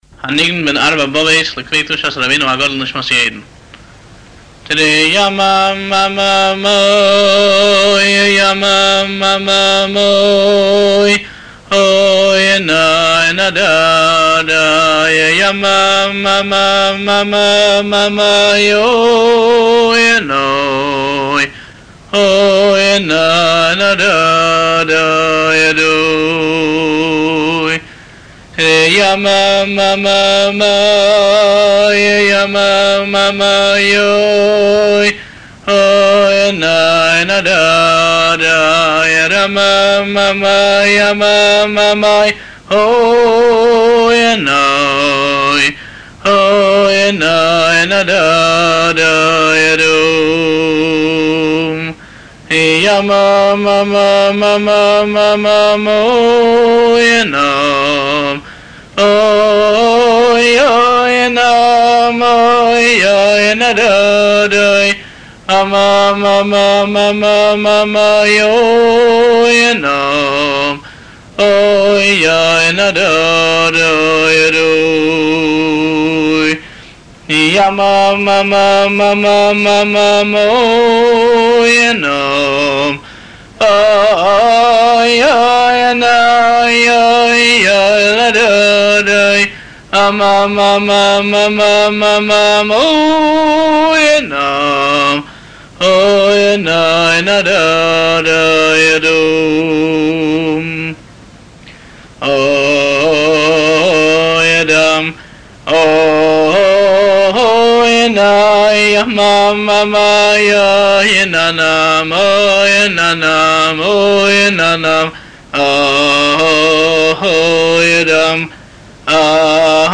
ניגון ארבע בבות